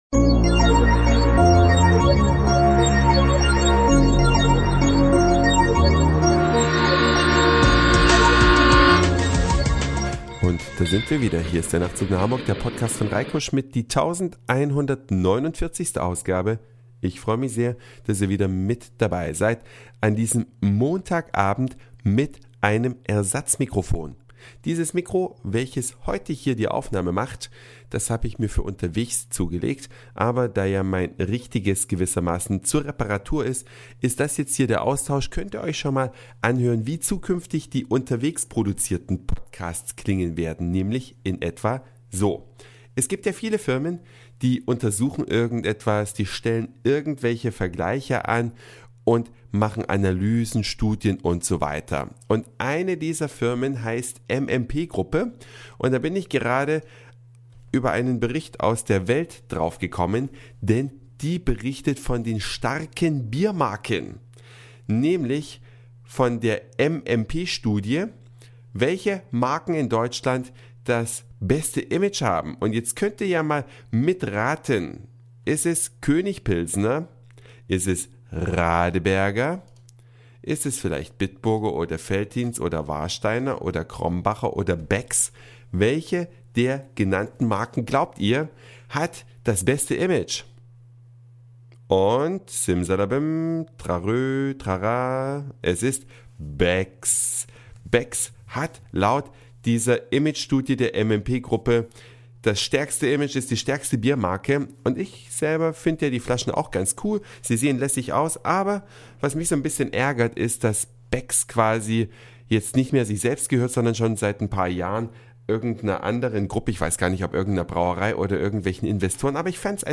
Heute mit Ersatzmikrofon.